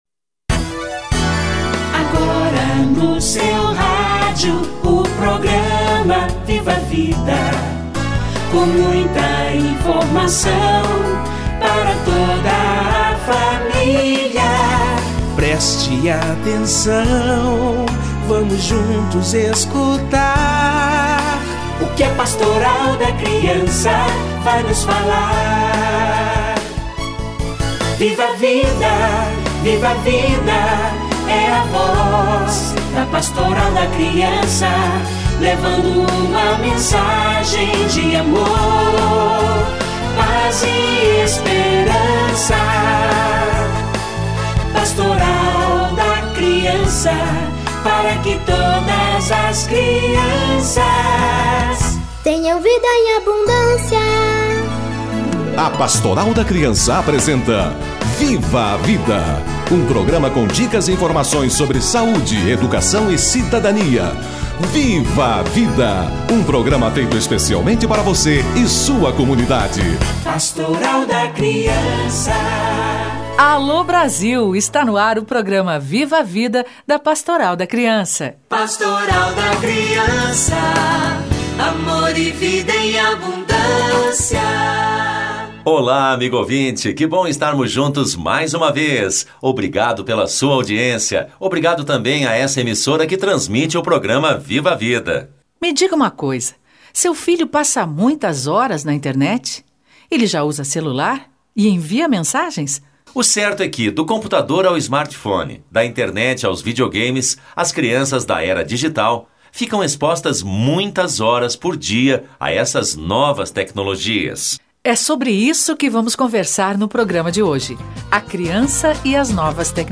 Crianças e novas tecnologias - Entrevista